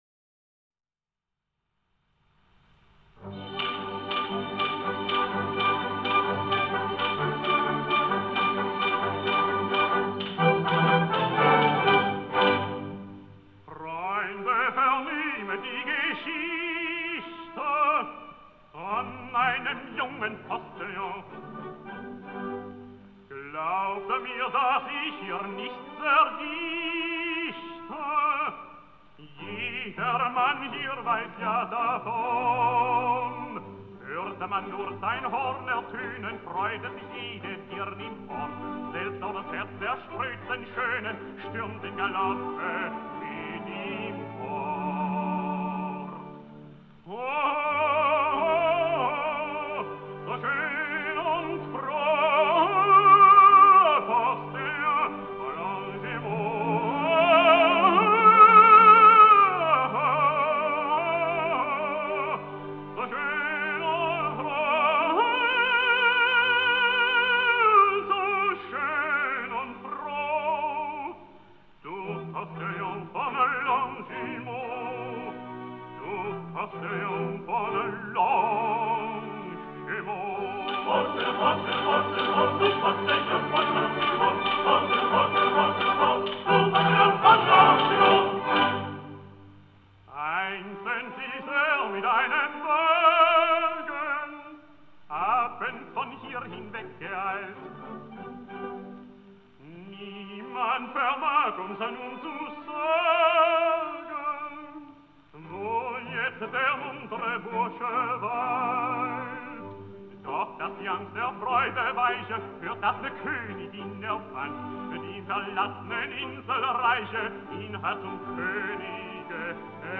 Sung in German